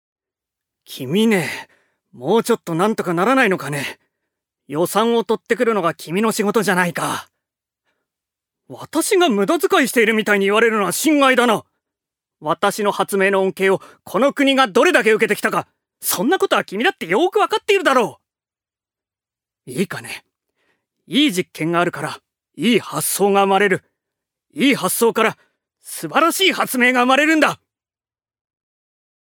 所属：男性タレント
セリフ２